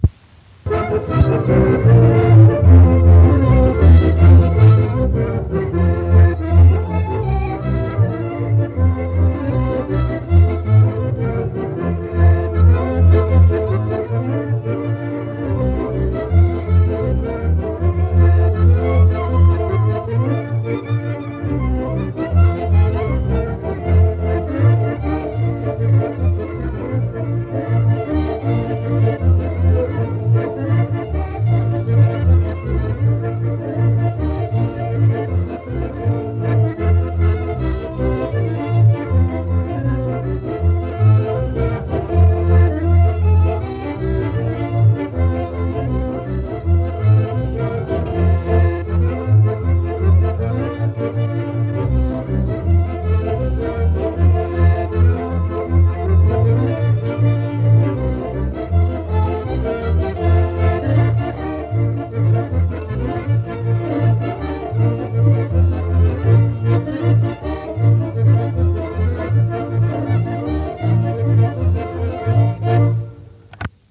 Instrumentalny 1
Nagranie archiwalne